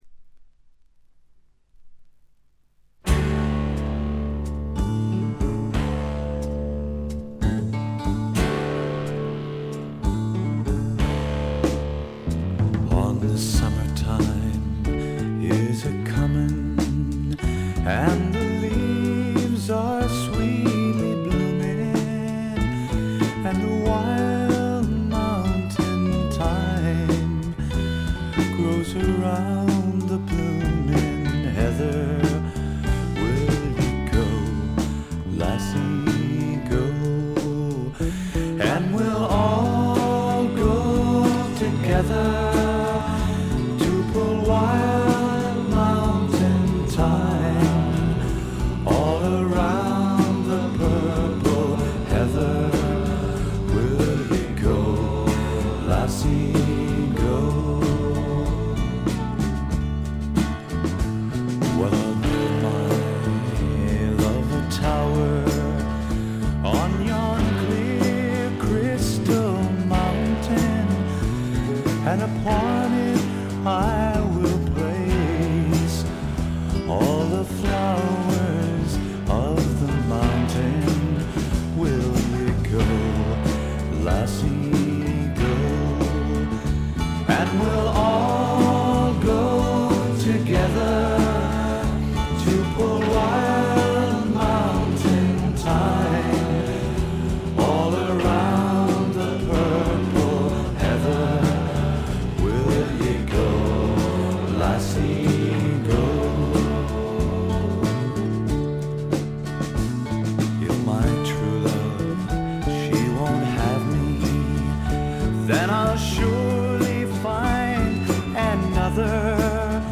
英国フォークロックの基本。
試聴曲は現品からの取り込み音源です。